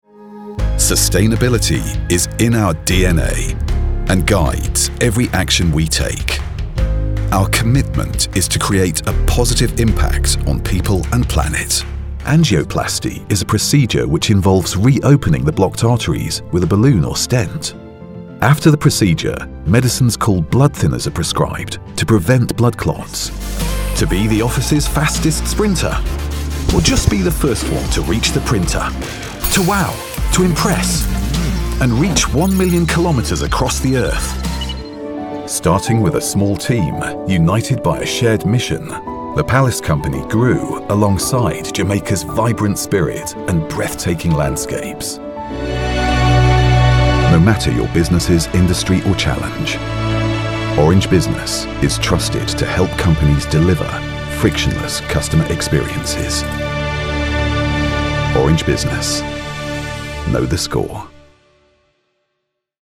Inglés (Británico)
Comercial, Profundo, Natural, Llamativo, Seguro
Corporativo